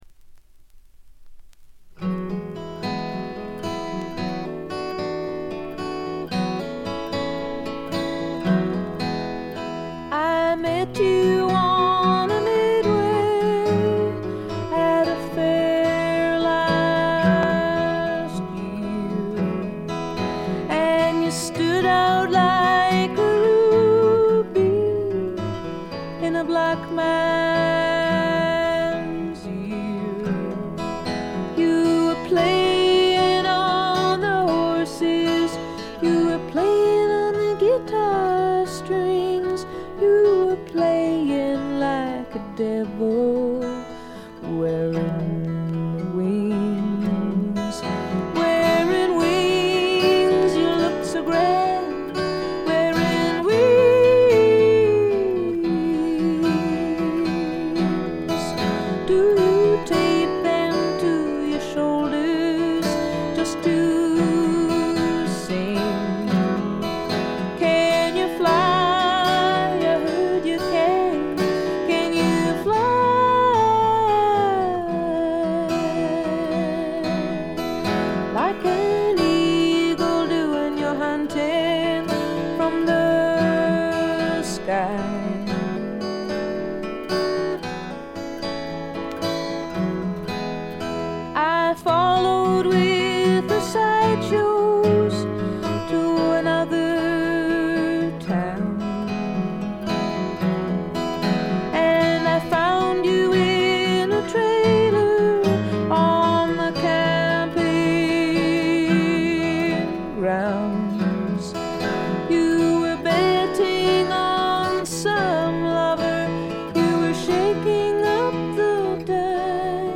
ところどころで軽微なバックグラウンドノイズに気づく程度。
透明感のあるみずみずしさが初期の最大の魅力です。
女性フォーク／シンガーソングライター・ファンなら避けては通れない基本盤でもあります。
試聴曲は現品からの取り込み音源です。
※17秒あたりでプツ音が出ますが、洗浄で取り切れなかった微細なゴミが食いついていたためで、その後除去して音にも出ません。
guitar, keyboards, vocals